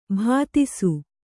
♪ bhātisu